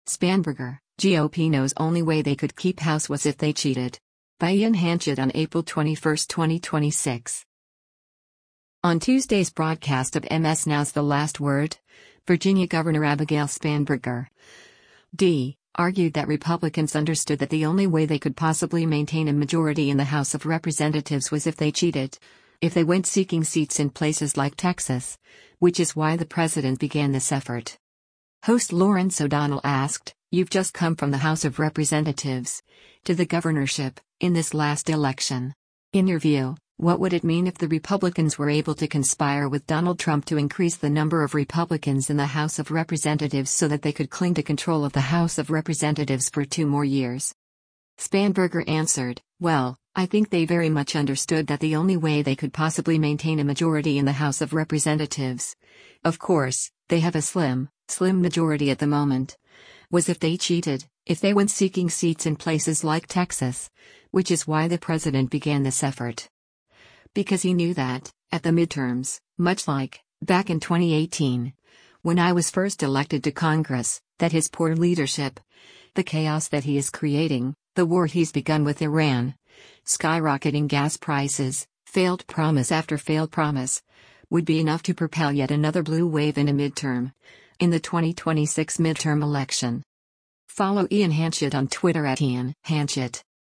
Host Lawrence O’Donnell asked, “You’ve just come from the House of Representatives, to the governorship, in this last election.